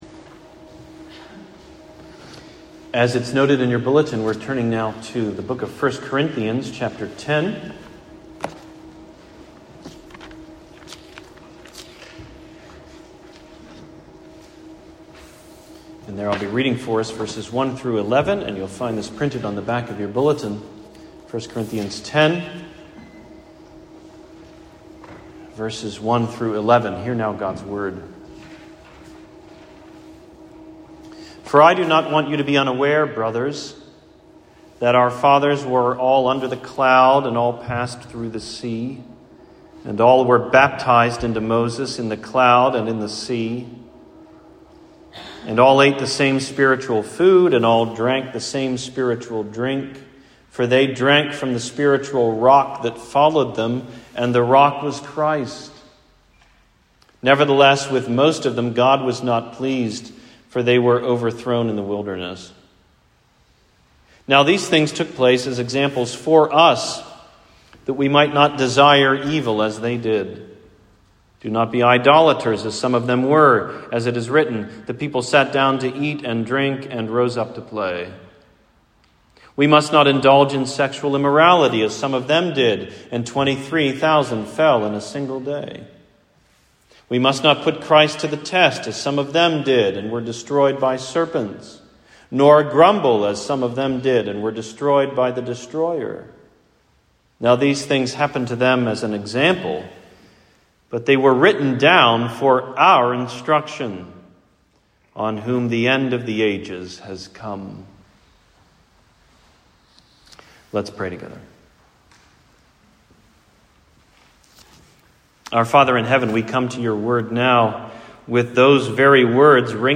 Written for Our Instruction: Sermon on 1 Corinthians 10:1-11